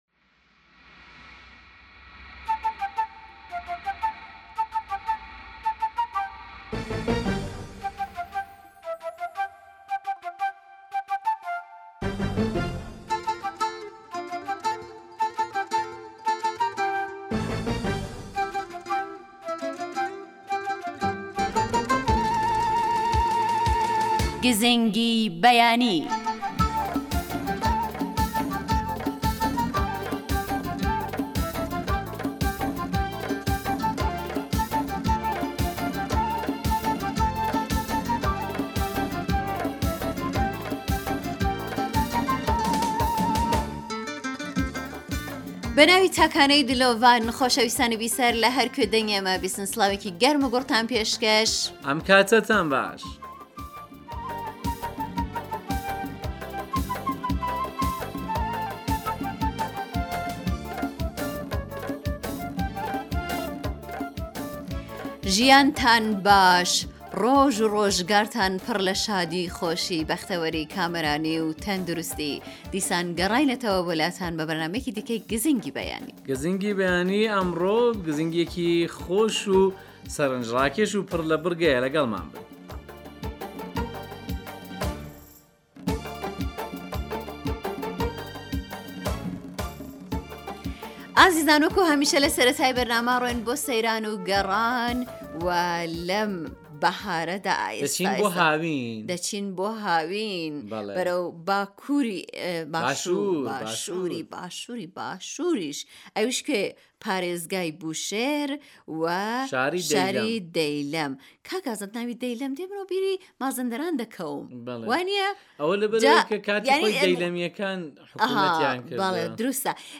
گزینگی بەیانی بەرنامەیێكی تایبەتی بەیانانە كە هەموو ڕۆژێك لە ڕادیۆ كەردی تاران بڵاو دەبێتەوە و بریتییە لە ڕاپۆرت و دەنگی گوێگران و تاووتوێ كردنی بابەتێكی پ...